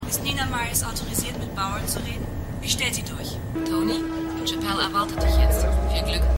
24_1x14_WblTelefonstimme.mp3